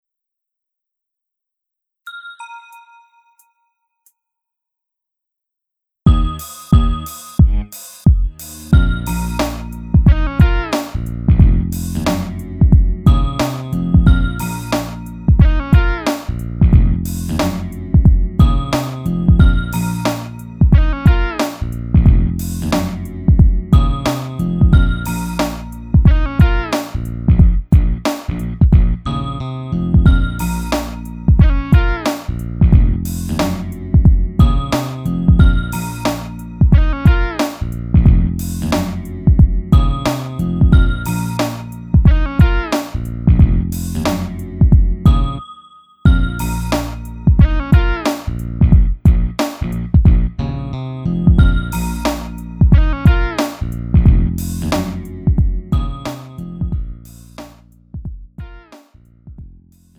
음정 -1키 2:09
장르 가요 구분 Lite MR
Lite MR은 저렴한 가격에 간단한 연습이나 취미용으로 활용할 수 있는 가벼운 반주입니다.